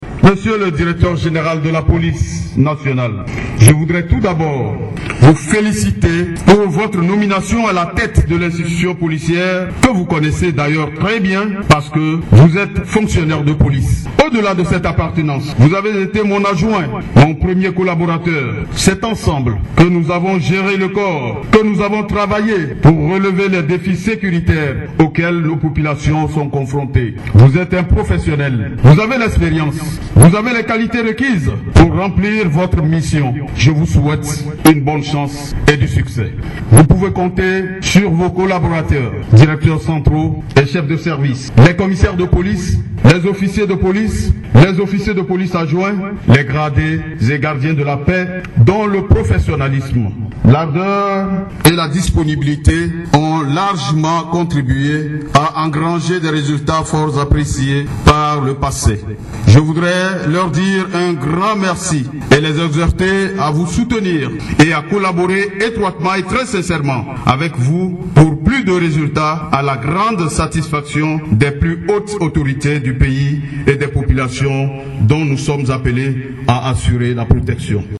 il est commissaire de police/ pendant 6 ans il a assuré la direction de la police avec le colonel Monpion Matéyindou en tant que son adjoint// aujourd’hui il le succède// nommée vendredi, il a pris fonction à la suite d’une cérémonie de prise d’arme à la direction générale de la police nationale sous les regards du ministre de la sécurité// Le directeur général sortant Col Monpion Matéyindou a été à la commande de la police pendant 6 ans/ et pour avoir collaborer avec son successeur, il a loué ses qualité lors de son allocution// Col Monpion Matéyindou
Fr – Col Monpion Mateyindou (sur passation de pouvoir) -21 08 12